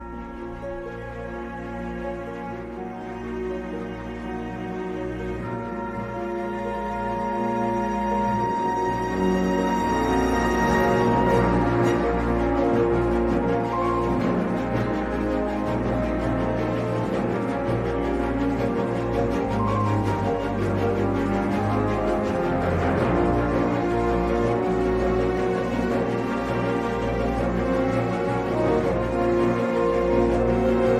Boss Theme
Source Ripped from the official soundtrack